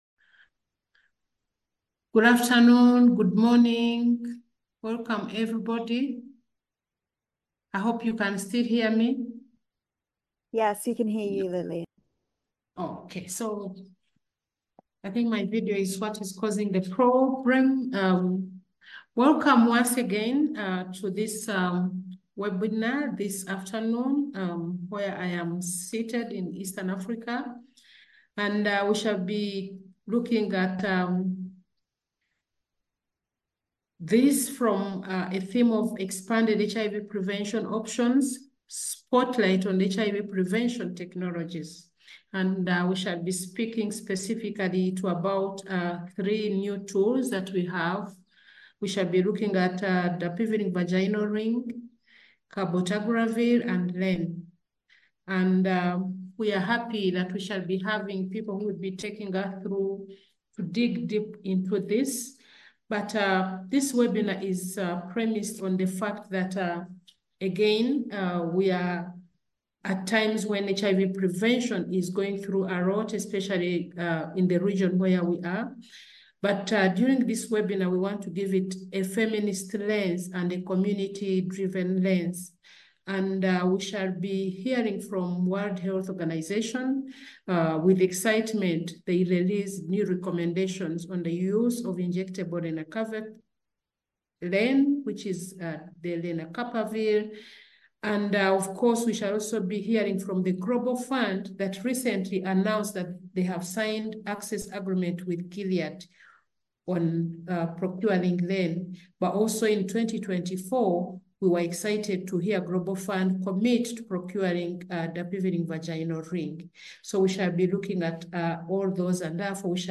HIV-Prevention-Webinar-Part-One.mp3